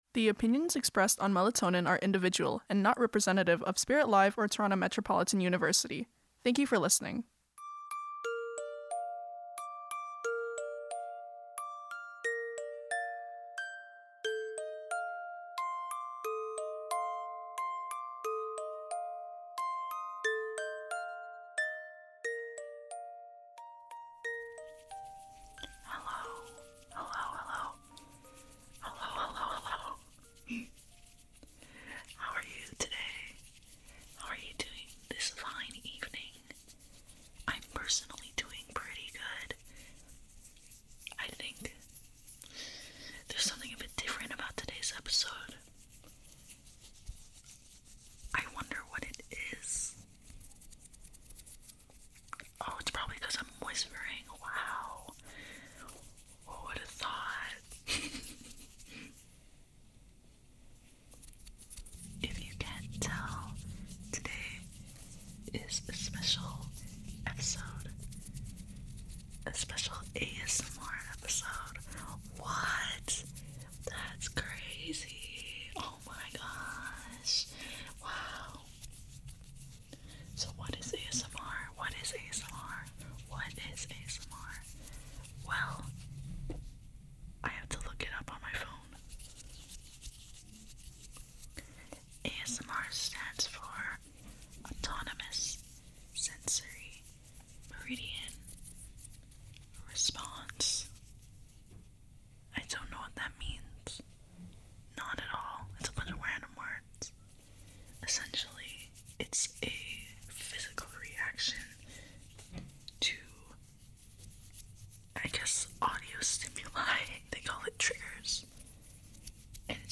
ASMR (An Introduction)
Triggers, In Order: – Serum Glass Bottle – Hair Rollers – Small and Big Makeup Brushes – Lotion Bottle – Ear Rubbing